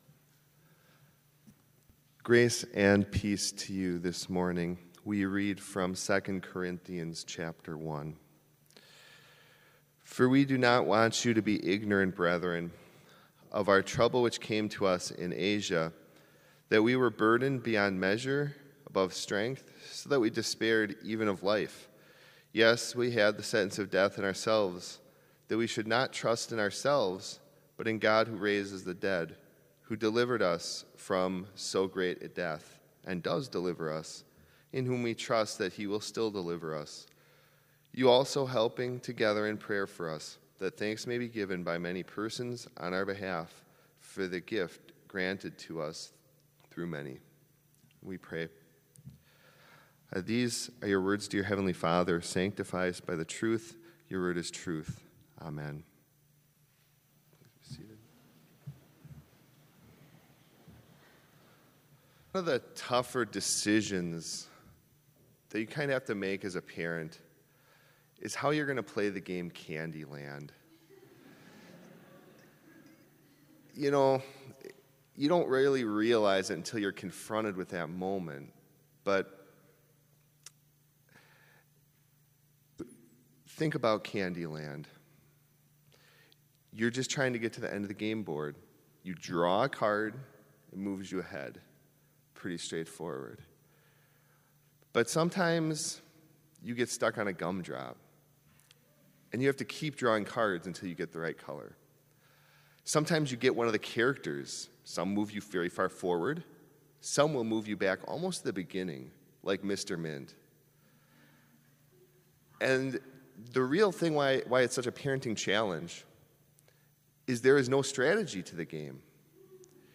Complete service audio for Chapel - October 24, 2019